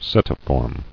[se·ti·form]